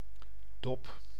Ääntäminen
IPA: /ˈdɔp/